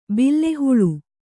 ♪ bille huḷu